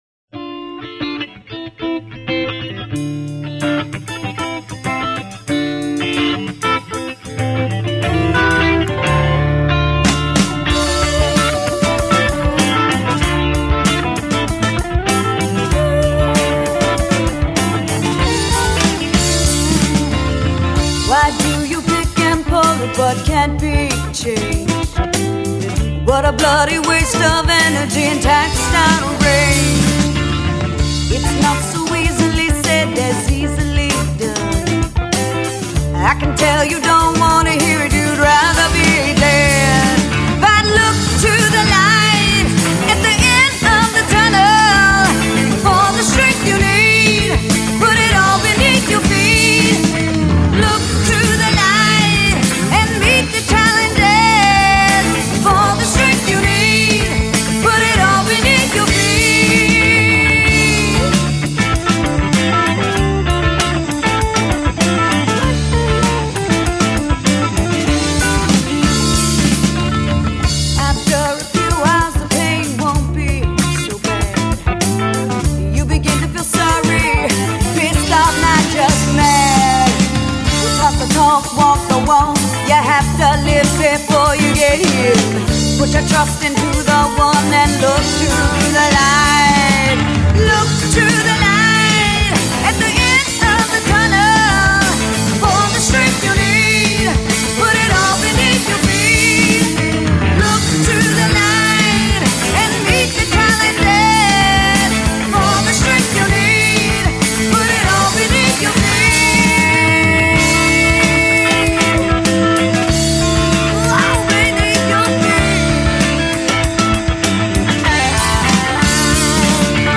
funk grooves, rock anthems